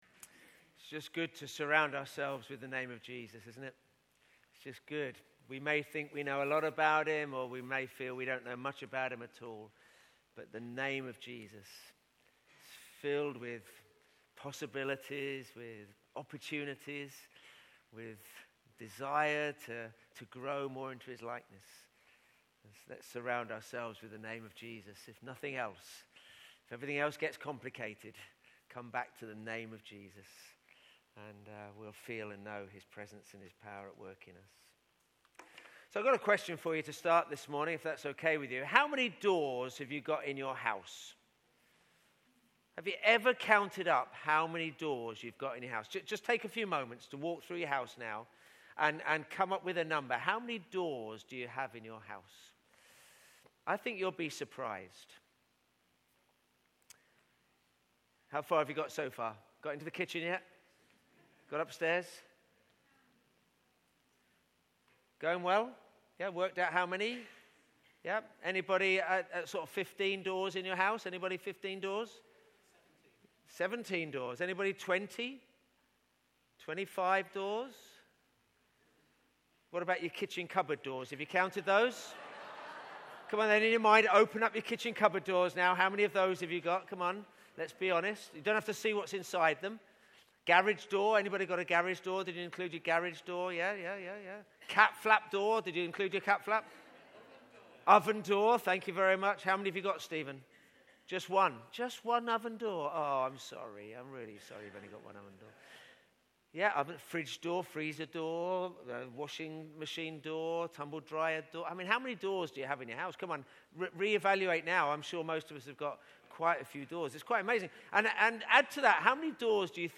Bible Text: John 10:7-10 | Preacher